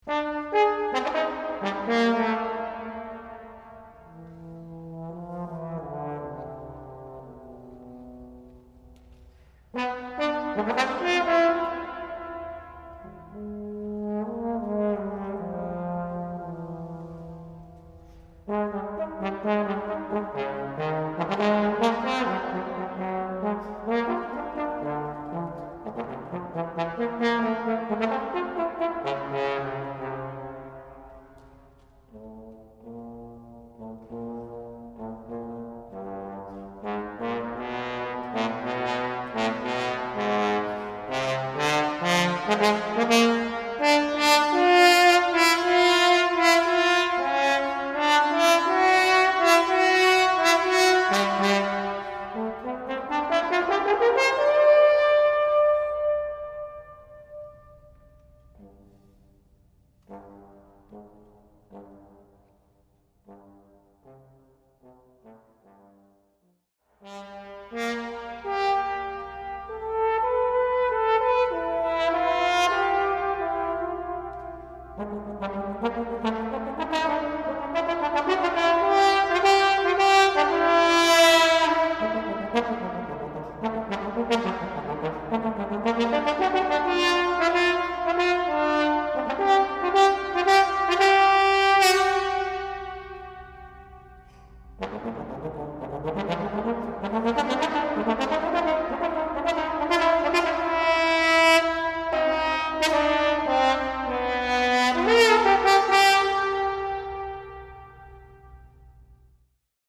Nick Woud Tenortrombone solo - Klassiek/Hedendaags
1992 Live, St. Jeroen, Noordwijk, 16 mei 2006